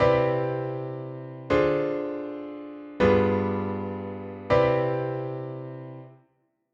This chord is G7 acting as the V chord in C major.
That was the V chord of C meaning we can use a tritone sub on this chord.